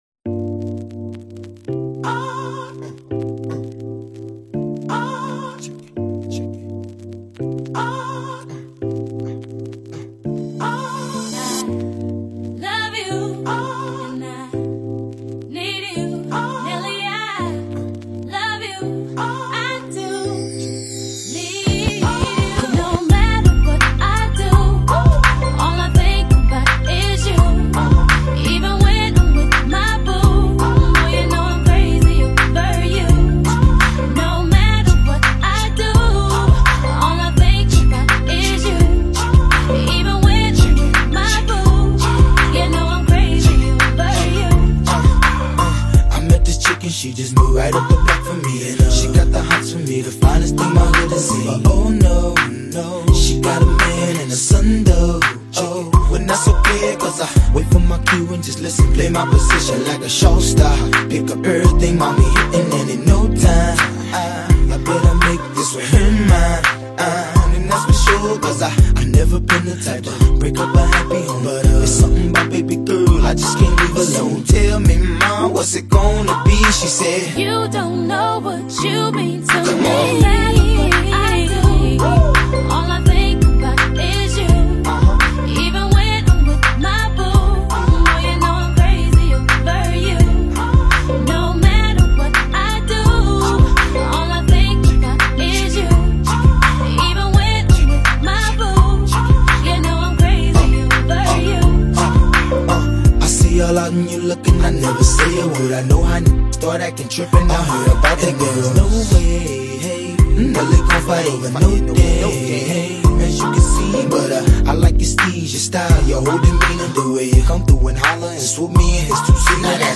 The beat dropped a smooth, infectious sample
the hot rapper with the country grammar flow